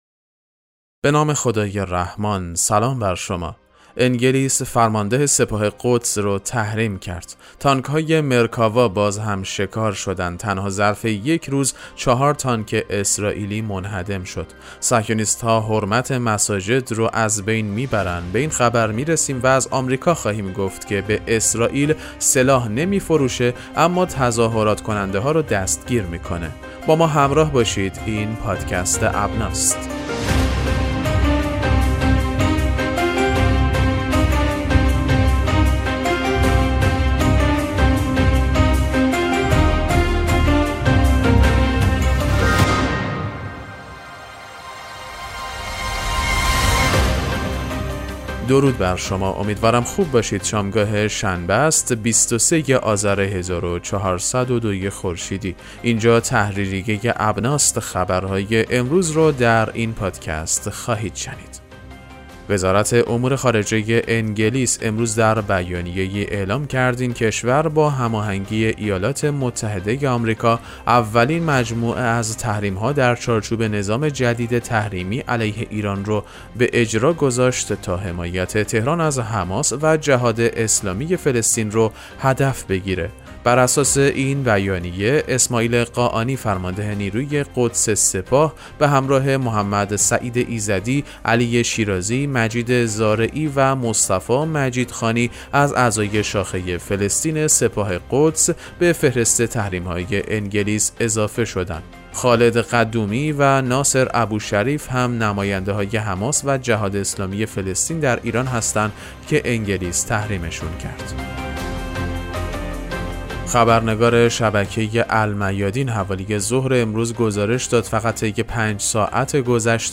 پادکست مهم‌ترین اخبار ابنا فارسی ــ 23 آذر 1402